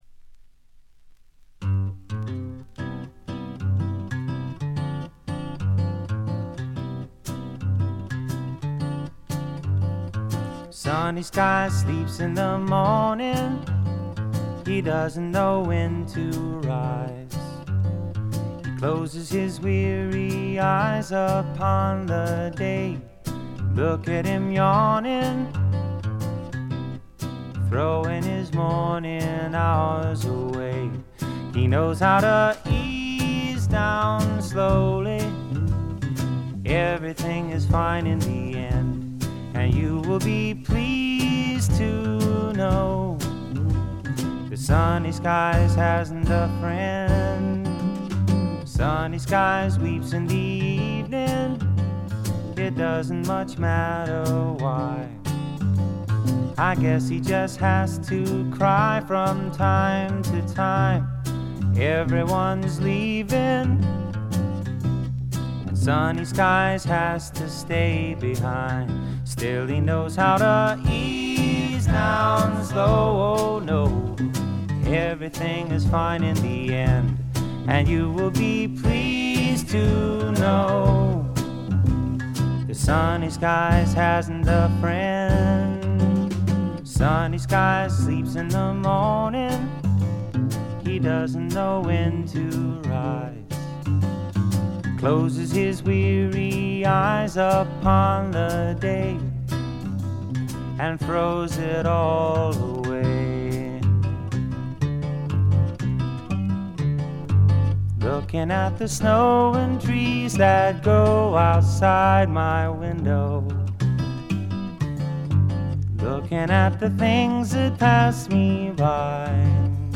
バックグラウンドノイズ、ところどころでチリプチ。
試聴曲は現品からの取り込み音源です。
Recorded at Sunset Sound, December '69